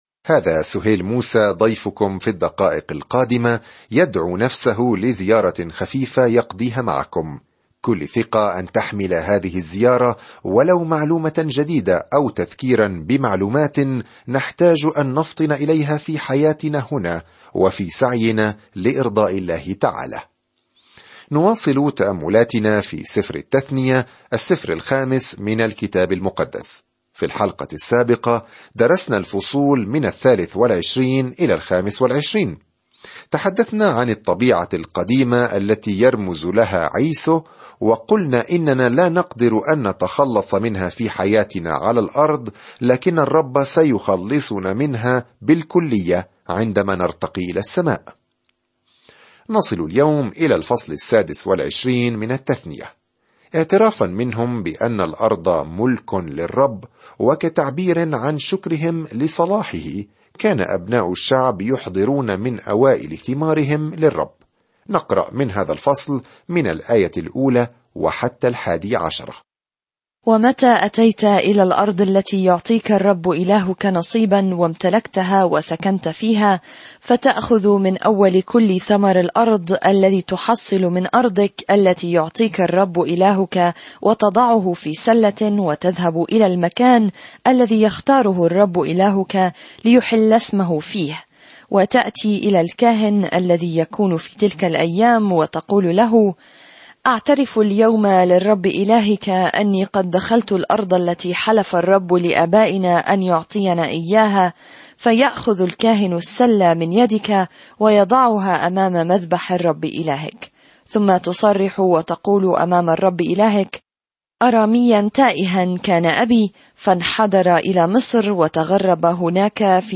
الكلمة اَلتَّثْنِيَة 26 اَلتَّثْنِيَة 27 اَلتَّثْنِيَة 28 يوم 15 ابدأ هذه الخطة يوم 17 عن هذه الخطة يلخص سفر التثنية شريعة الله الصالحة ويعلمنا أن الطاعة هي استجابتنا لمحبته. سافر يوميًا عبر سفر التثنية وأنت تستمع إلى الدراسة الصوتية وتقرأ آيات مختارة من كلمة الله.